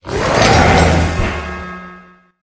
Grito de Zamazenta forma escudo supremo.ogg
) Categoría:Zamazenta Categoría:Gritos de Pokémon de la octava generación No puedes sobrescribir este archivo.
Grito_de_Zamazenta_forma_escudo_supremo.ogg